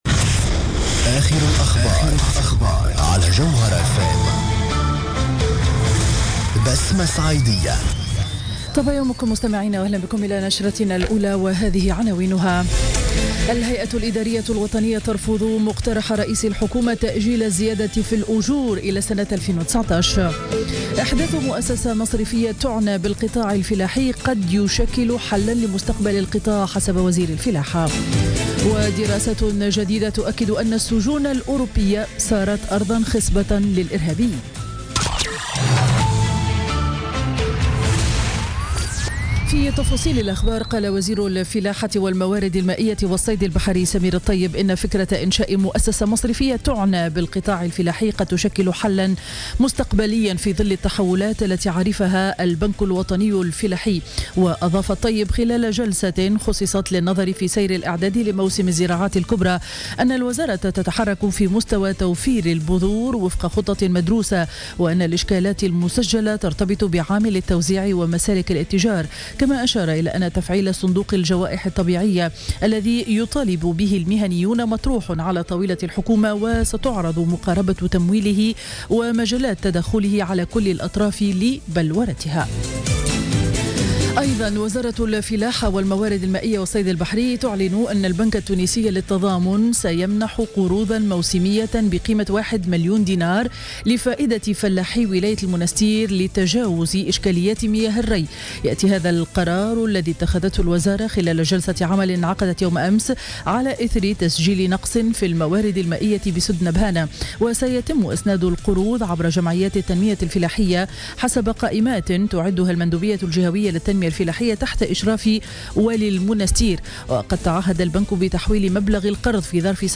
Journal Info 07h00 du mercredi 12 octobre 2016